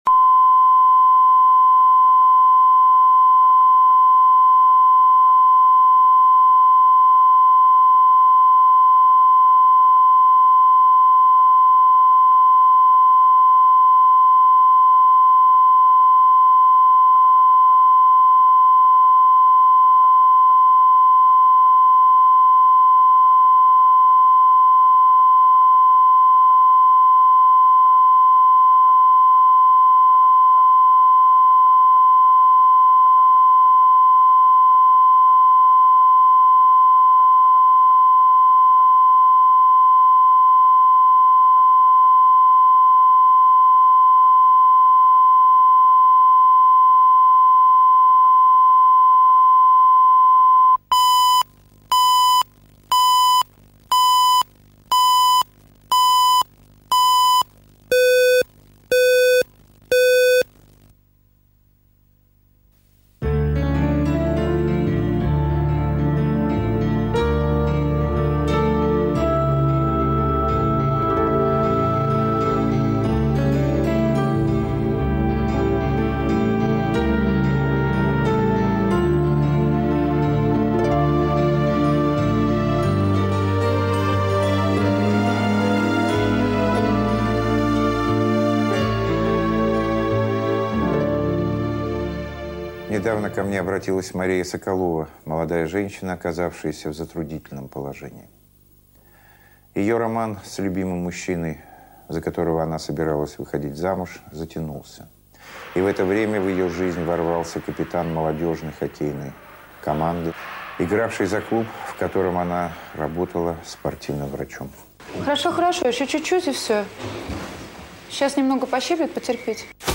Аудиокнига Капитаны